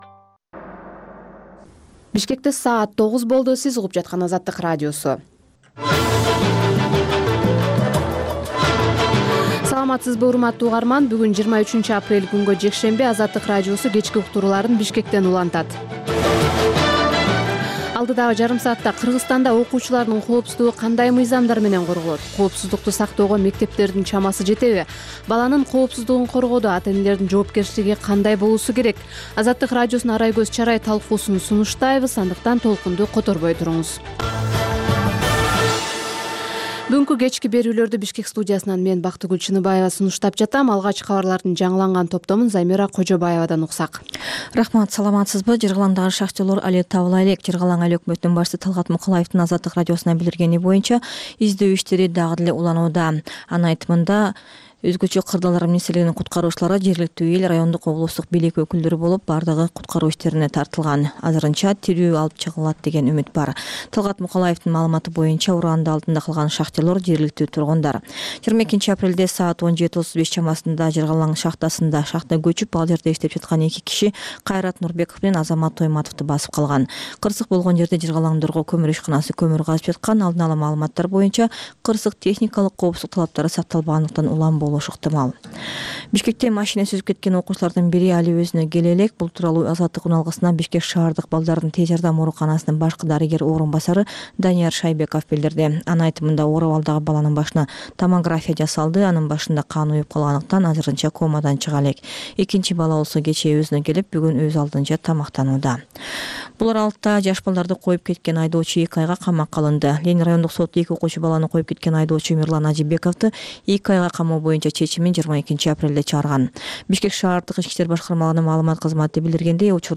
"Азаттык үналгысынын" бул кечки соңку жарым сааттык берүүсү жергиликтүү жана эл аралык кабарлар, репортаж, маек, аналитикалык баян, сереп, угармандардын ой-пикирлери, окурмандардын э-кат аркылуу келген пикирлеринин жалпыламасы жана башка берүүлөрдөн турат. Бул үналгы берүү ар күнү Бишкек убакыты боюнча саат 20:00дан 20:30га чейин обого түз чыгат.